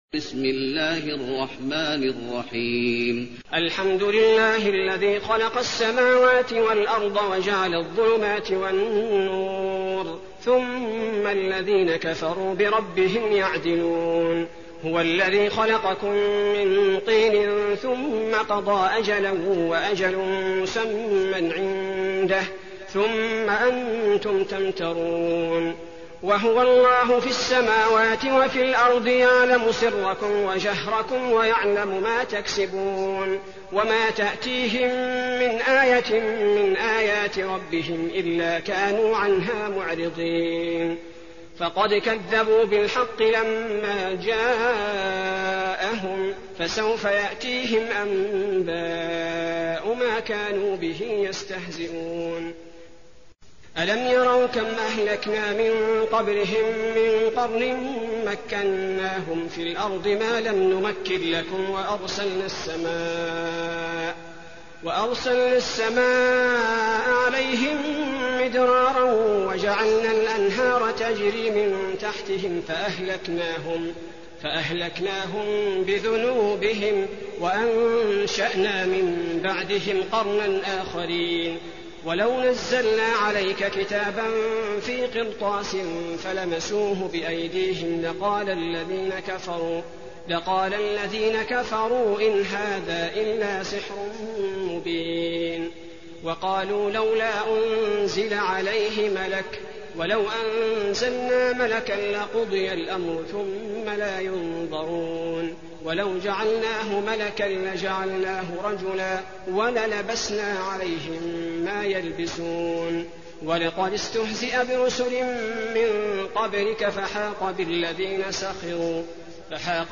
المكان: المسجد النبوي الأنعام